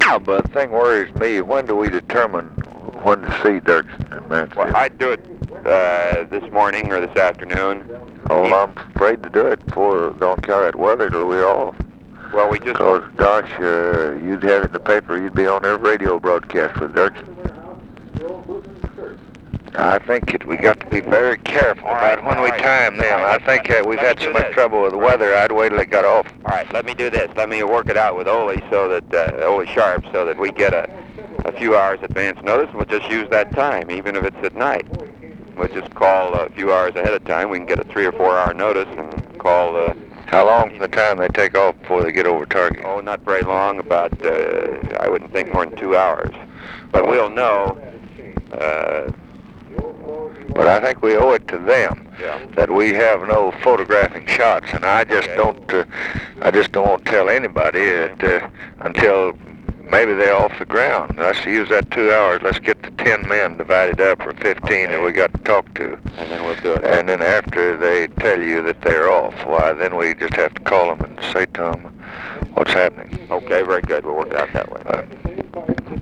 Conversation with ROBERT MCNAMARA, June 23, 1966
Secret White House Tapes